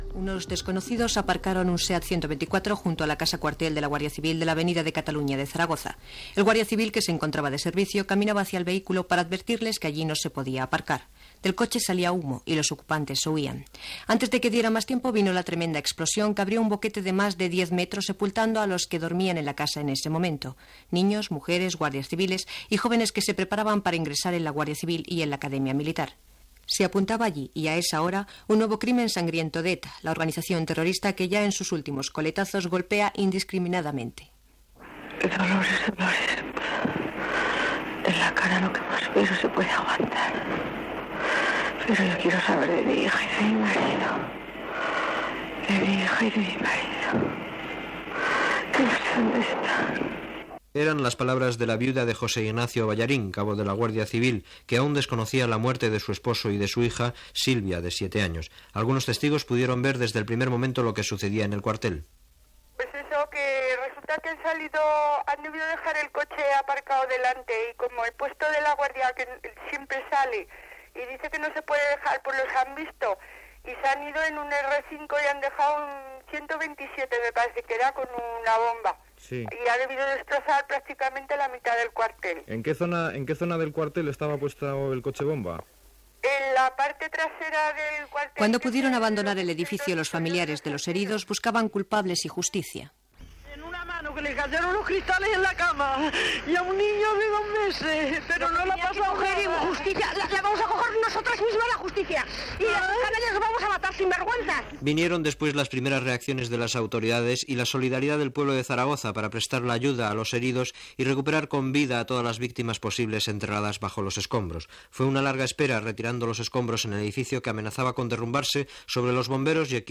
Reportatge sobre l'atemptat d'ETA a la casa quarter de la Guardia Civil a Saragossa el dia anterior. El polític Manuel Fraga Iribarne reclama la il·legalització del partit basc Herri Batasuna. Declaracions del Lehendakari José Antonio Ardanza
Informatiu